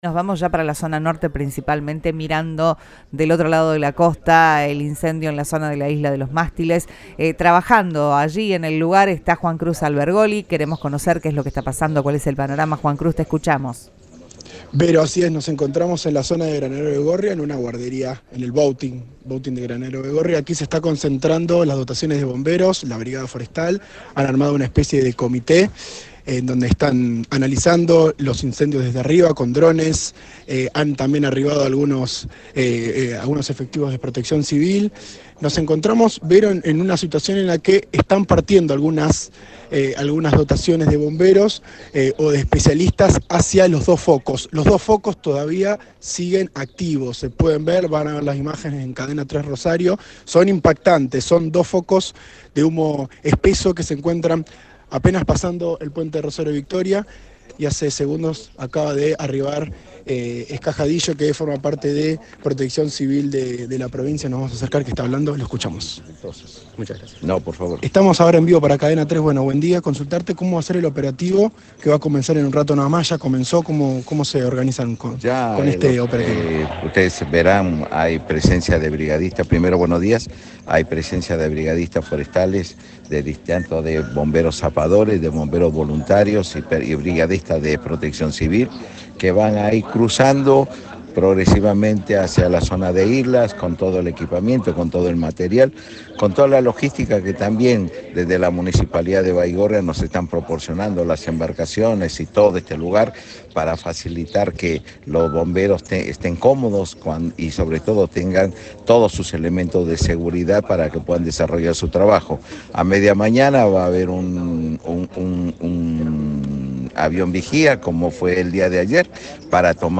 Marcos Escajadillo, secretario de Protección Civil y Gestión de Riesgo, habló con el móvil de Cadena 3 Rosario, en Siempre Juntos, y explicó: "Vamos a empezar a cruzar Bomberos para que puedan trabajar y vamos a sumar un avión vigía para monitorear".